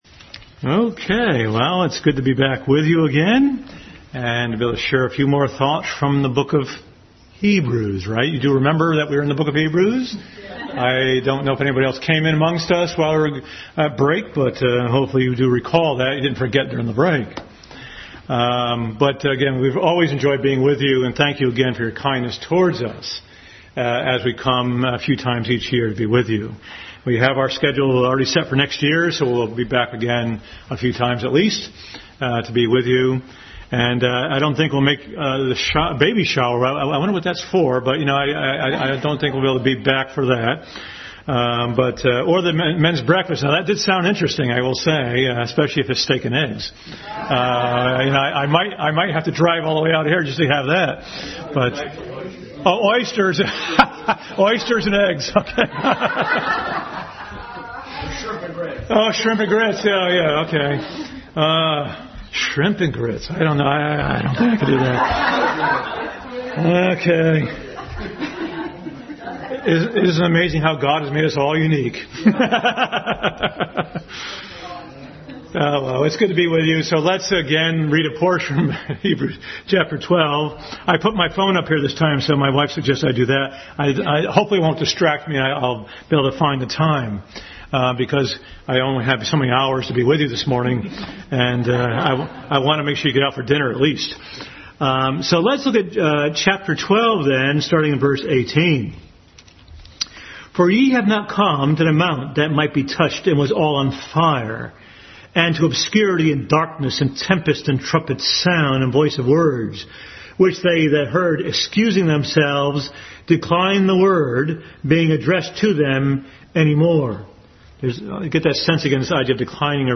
Hebrews 12:18-29 Passage: Hebrews 12:18-29 Service Type: Family Bible Hour Family Bible Hour message.